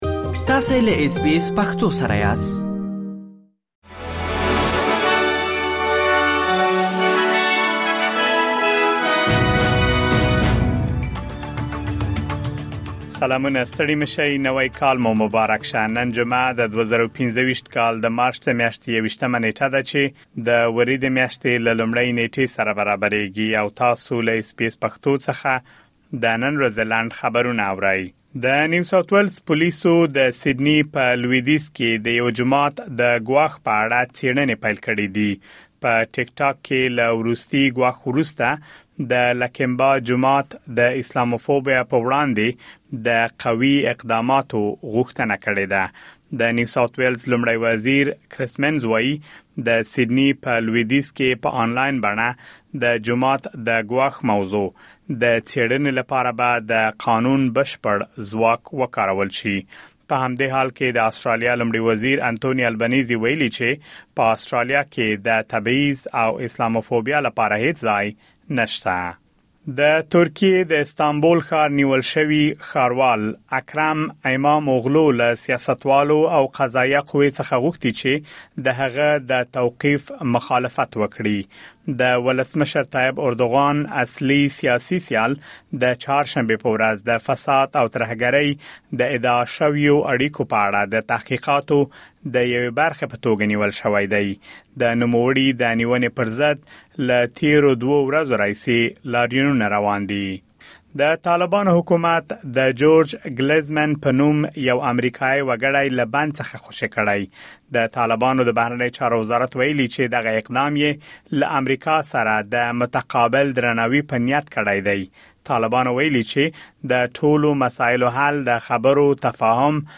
د اس بي اس پښتو د نن ورځې لنډ خبرونه | ۲۱ مارچ ۲۰۲۵
د اس بي اس پښتو د نن ورځې لنډ خبرونه دلته واورئ.